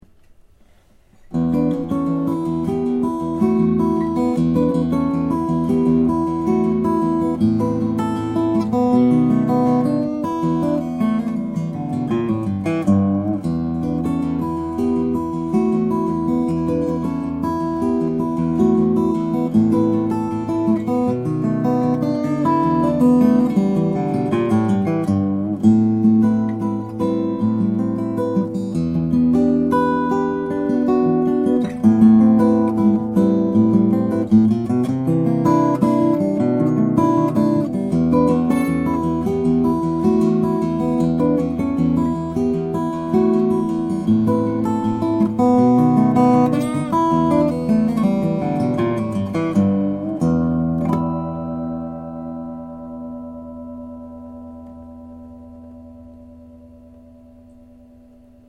This makes for one of the purest amplified tones we’ve heard, just wonderful. The Maple body and Sitka top produce a strong, crisp and fast note and…